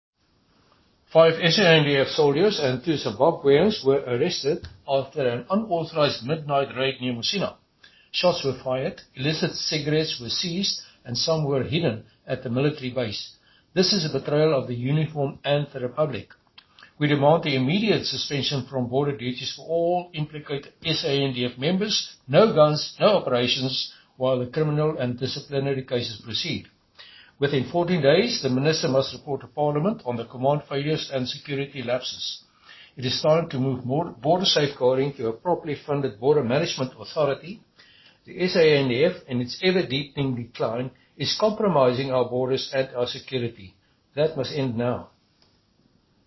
Issued by Chris Hattingh MP – DA Spokesperson on Defense and Military Veterans
Afrikaans soundbites by Chris Hattingh MP.